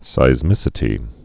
(sīz-mĭsĭ-tē)